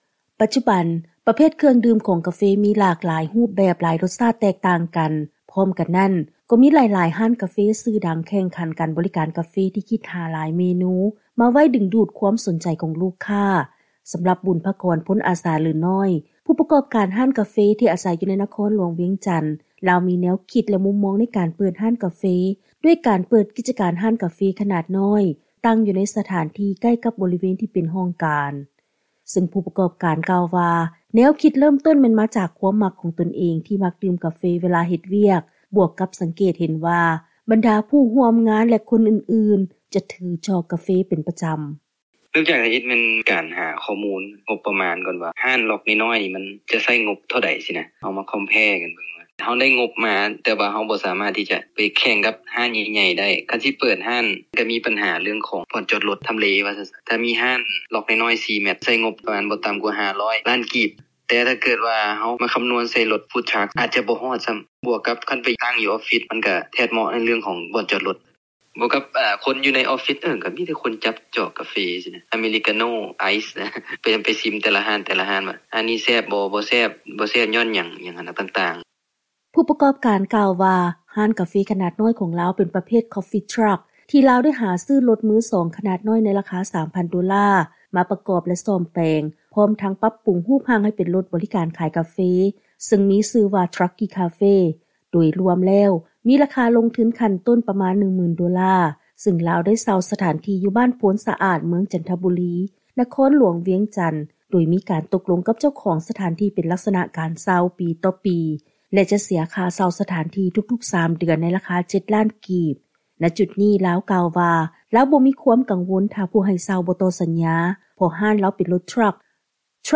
ຟັງລາຍງານກ່ຽວກັບ ທຸລະກິດຮ້ານກາເຟຂະໜາດນ້ອຍ